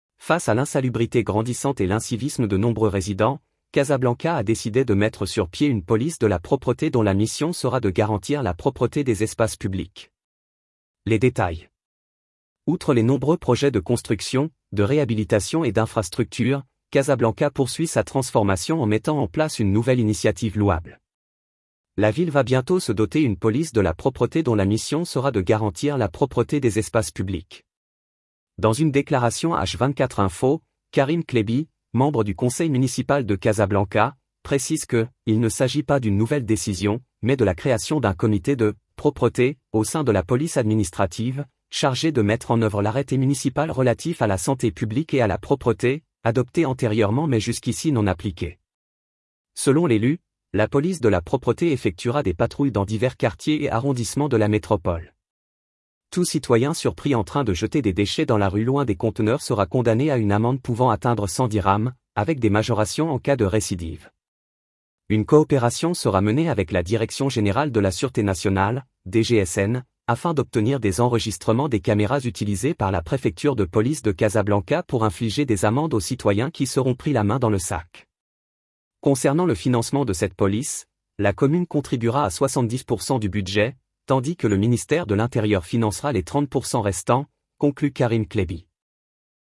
Article en Audio